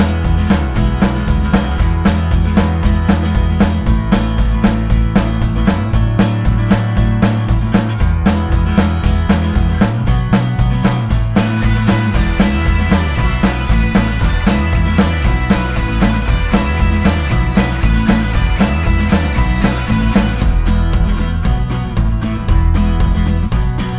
Pop (2010s)